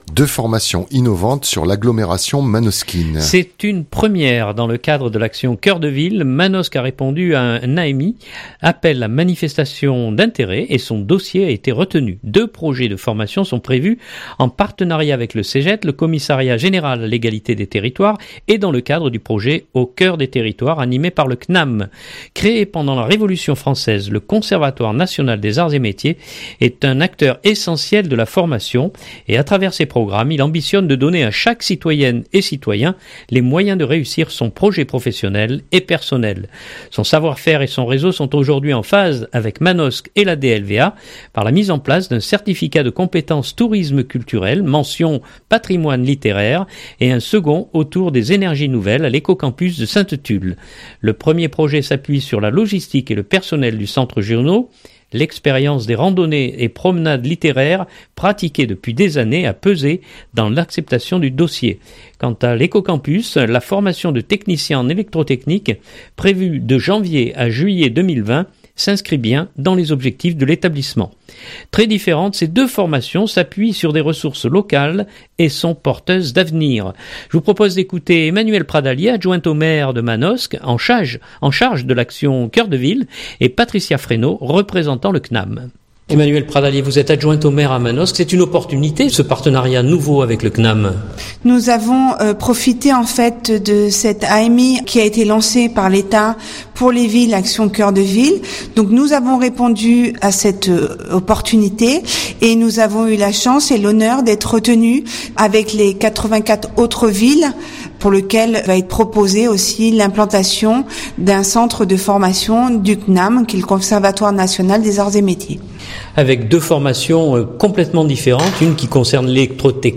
Je vous propose d’écouter Emmanuelle Pradalier, adjointe au maire de Manosque en charge de l’Action Cœur de Ville